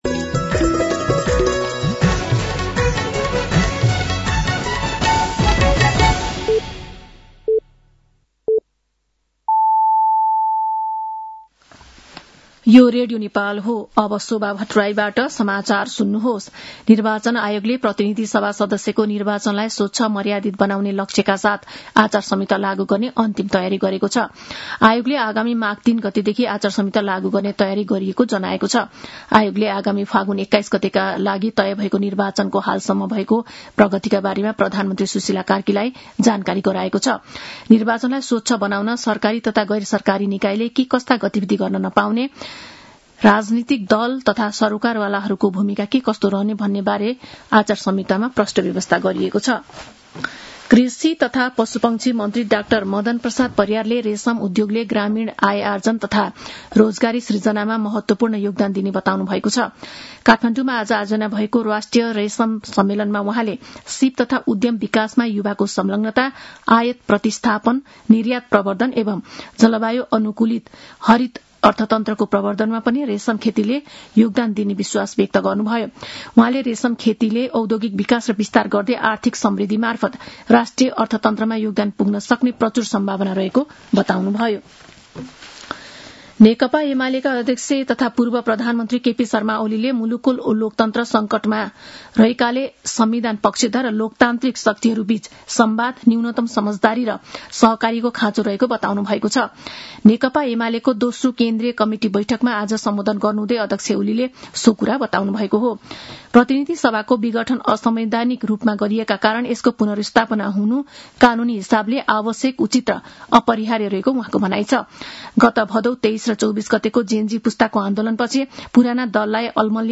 साँझ ५ बजेको नेपाली समाचार : २६ पुष , २०८२
5.-pm-nepali-news-1-2.mp3